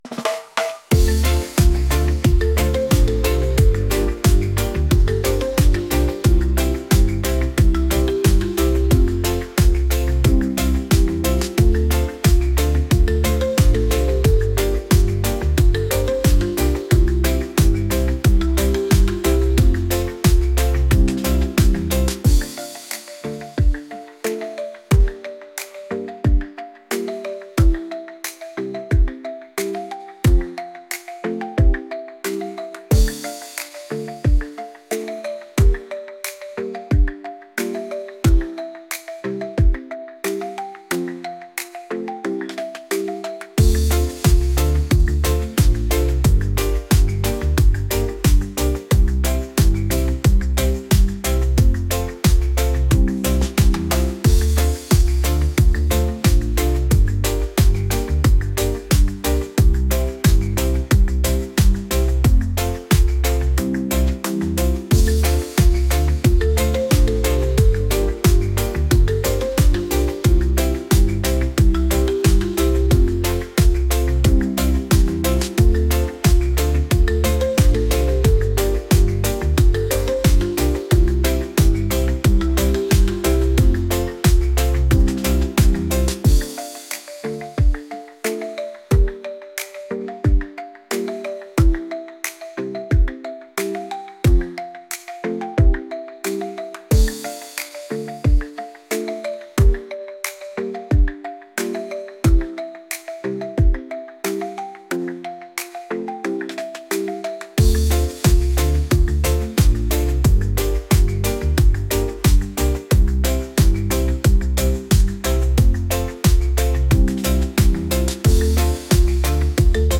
reggae | pop | latin